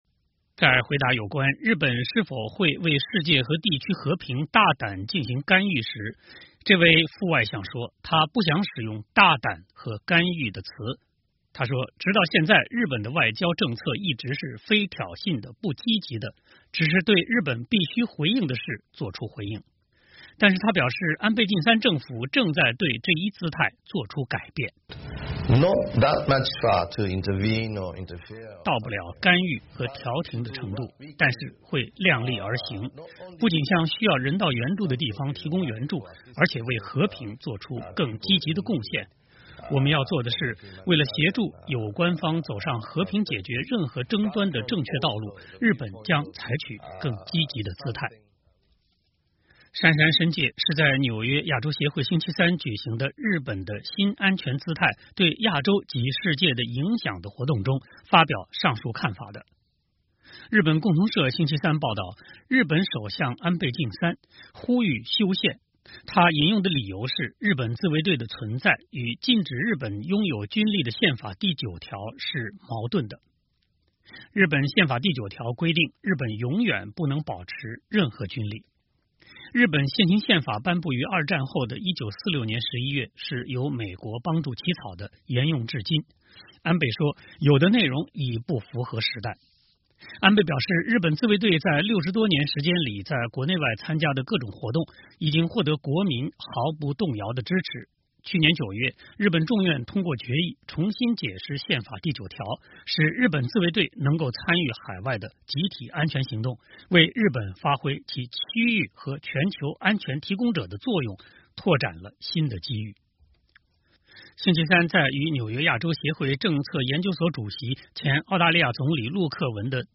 亚洲协会政策研究所主席陆克文与日本副外相杉山伸介对谈
杉山伸介是在纽约亚洲协会星期三举行的“日本的新安全姿态：对亚洲及世界的影响”活动中发表上述看法的。